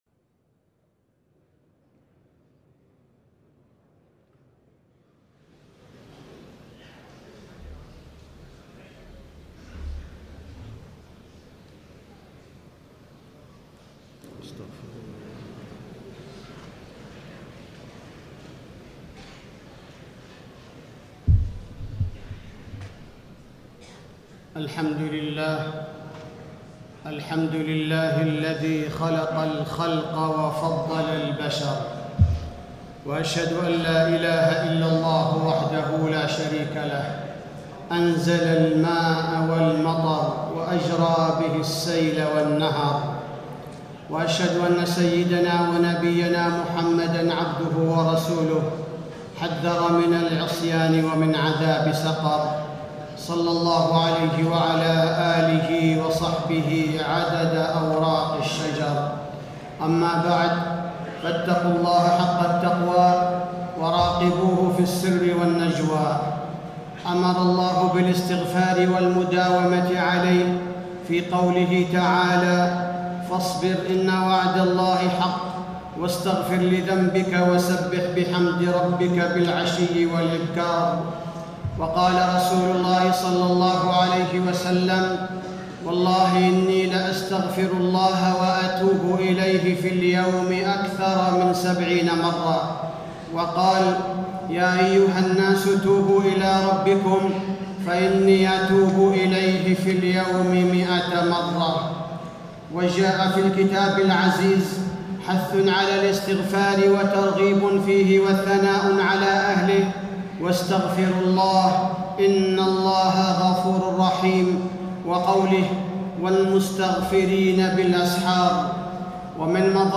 خطبة الاستسقاء - المدينة- الشيخ عبدالباريء الثبيتي - الموقع الرسمي لرئاسة الشؤون الدينية بالمسجد النبوي والمسجد الحرام
تاريخ النشر ٢٣ ربيع الثاني ١٤٣٦ هـ المكان: المسجد النبوي الشيخ: فضيلة الشيخ عبدالباري الثبيتي فضيلة الشيخ عبدالباري الثبيتي خطبة الاستسقاء - المدينة- الشيخ عبدالباريء الثبيتي